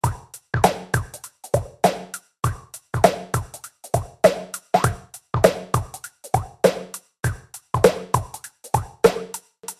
描述：嘻哈华语节拍
Tag: 100 bpm Hip Hop Loops Drum Loops 1.81 MB wav Key : Unknown